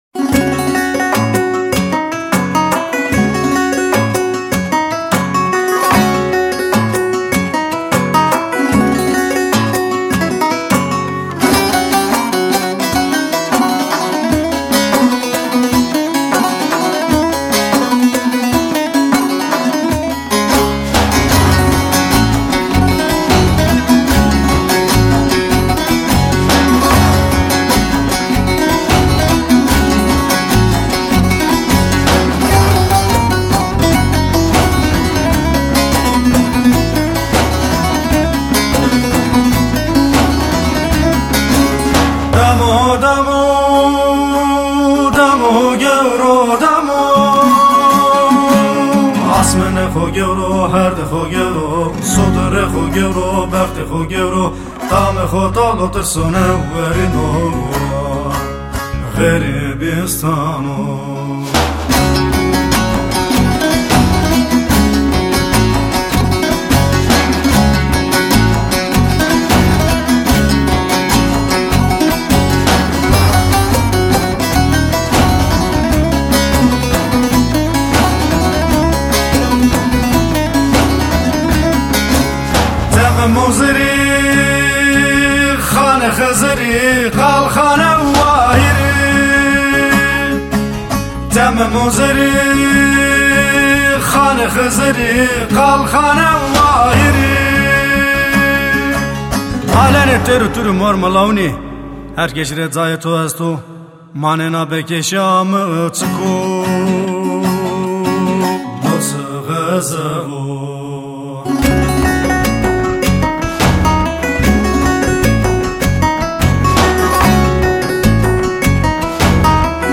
Zazakisch-kurdische Weltmusik aus dem Osten Anatoliens.
Tembur, Saxofon, Gesang
Tembur, Erbane, Gesang
Duduk, Zirne
Fretless-Bass
Schlagzeug, Perkussion